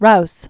(rous), (Francis) Peyton 1879-1970.